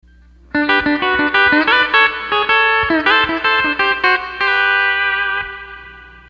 Here's a riff that's very similar to one he did on "Let The World Keep On Turning":
A lot of what you do is amazing and revisiting the pages just now, I can see I've come along a little bit, enough to apprehend more of the passages and certain parts, like the Brumley style lick you just put up.